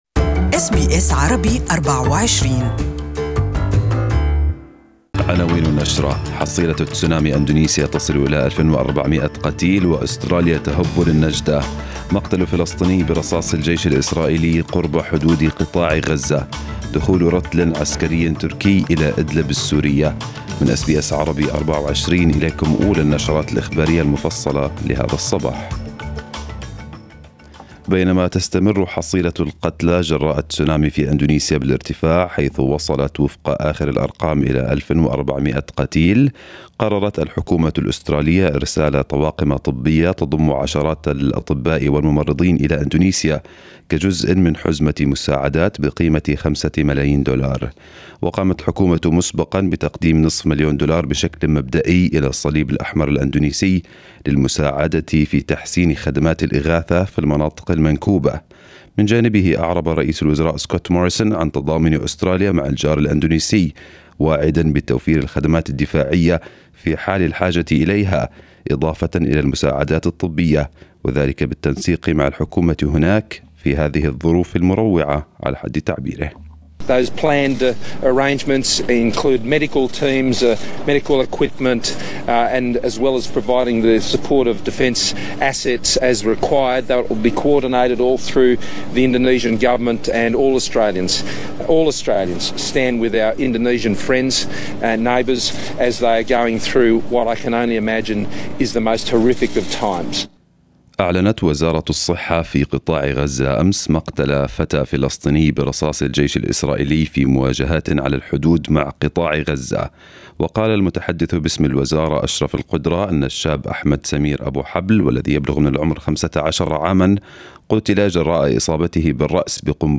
News bulletin of this morning